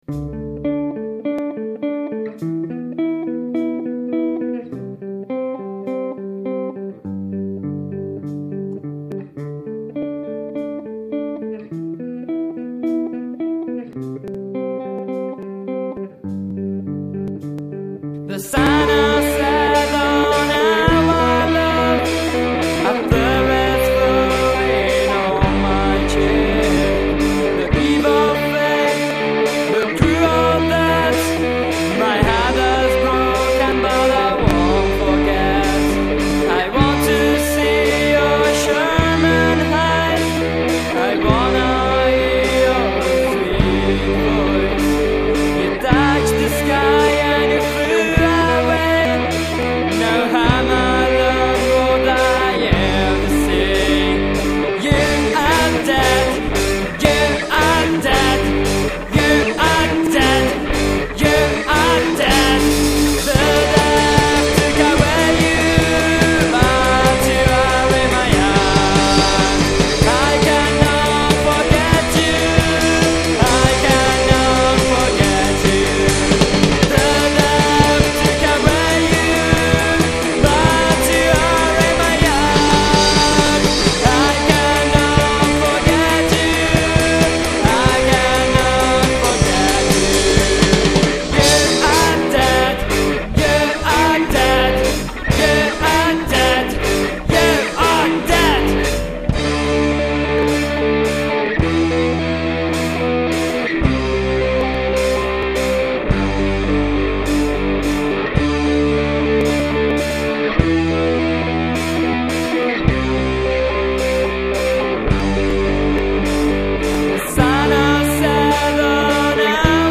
Genere: Hard Rock